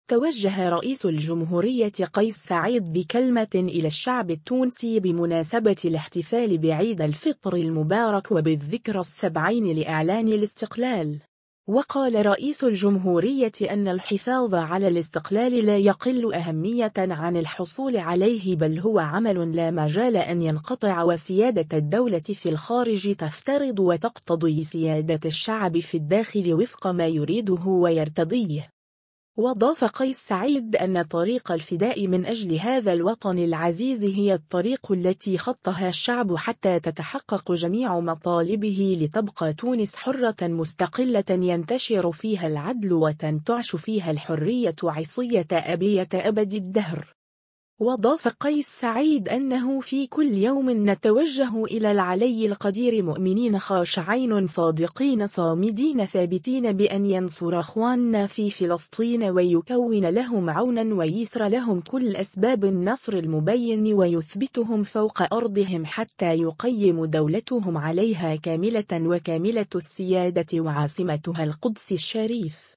توجه رئيس الجمهوريّة قيس سعيّد بكلمة إلى الشعب التونسي بمناسبة الاحتفال بعيد الفطر المبارك وبالذكرى السبعين لإعلان الاستقلال.